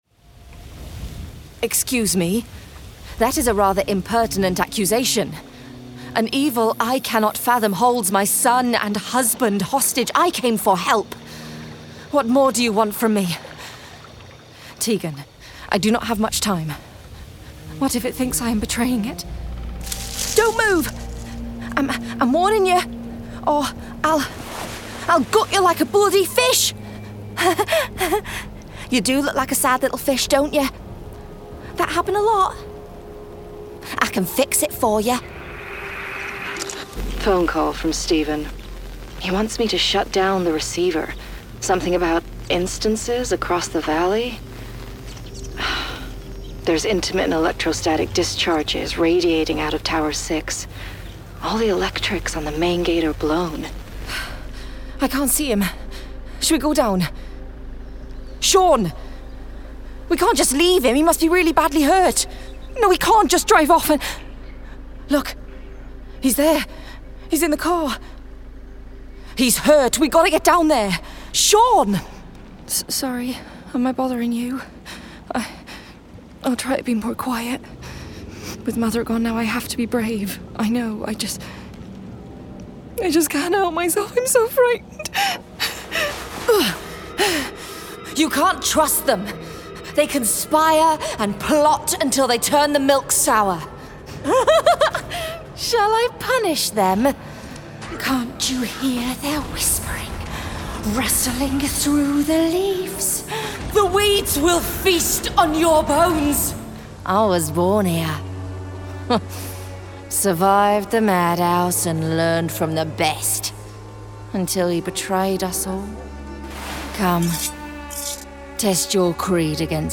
Video Game Reel
• Native Accent: Geordie, R.P
• Home Studio
With an instinctive grasp of character, she too has a talent for the off-beat and the quirky.